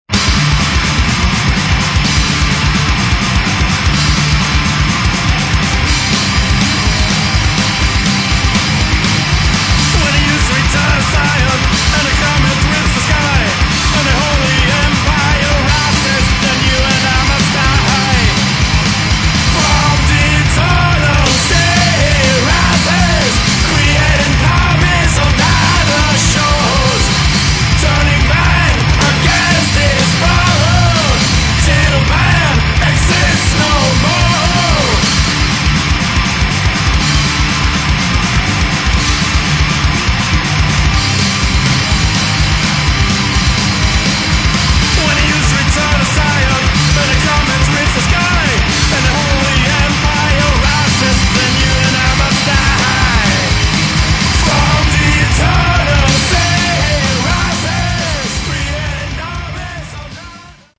Metal
Очень тяжелая и жесткая музыка, с хорошим вокалом.